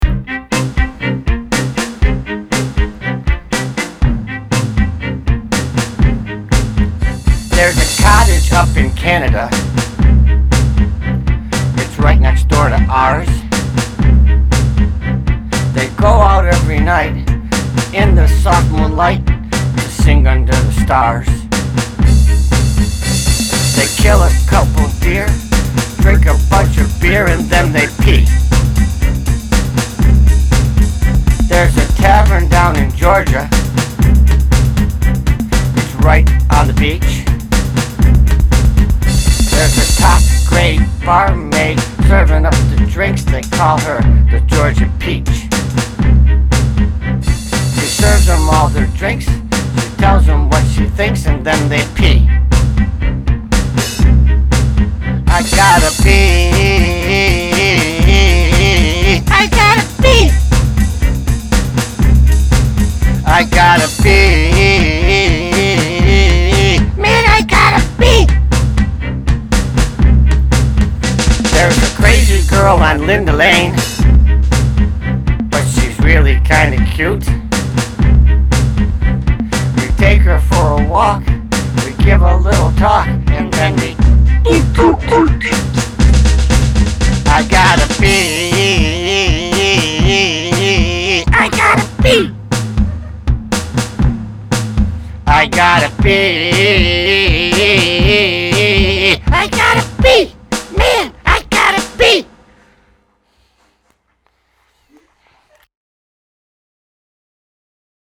Vocals, Guitar
Keys, Bass Guitar, Drums